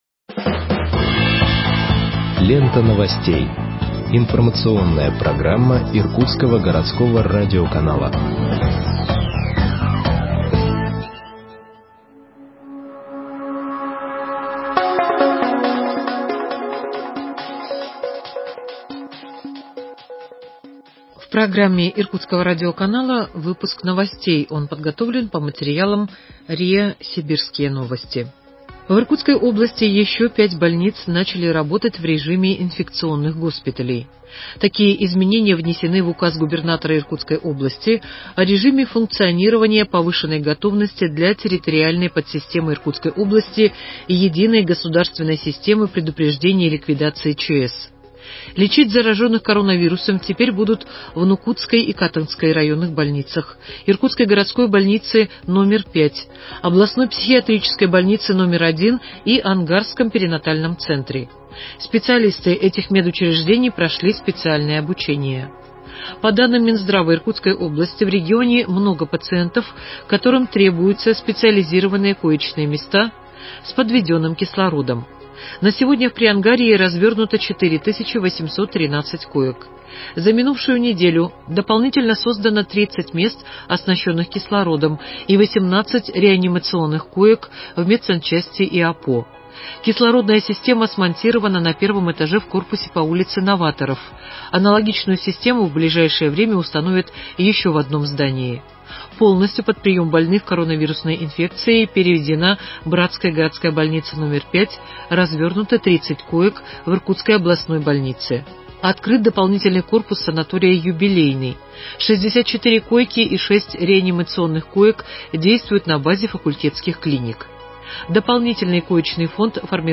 Выпуск новостей в подкастах газеты Иркутск от 03.11.2020 № 2